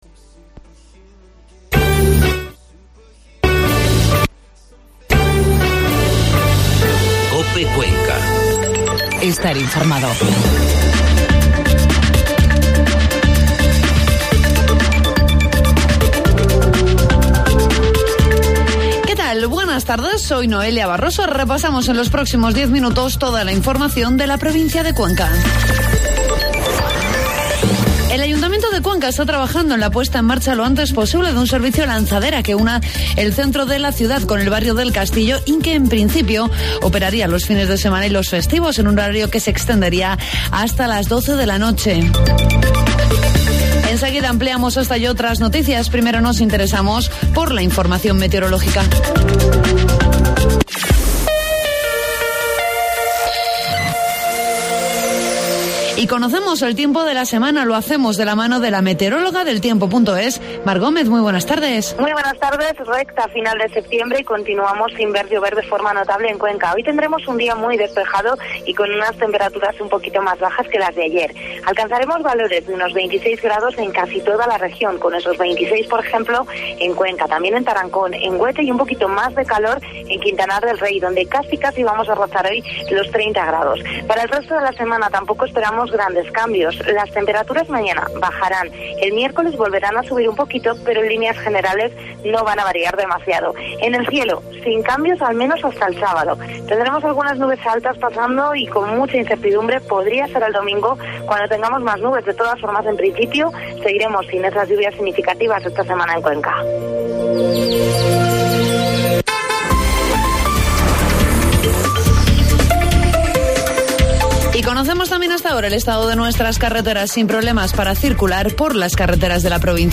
AUDIO: Informativo mediodía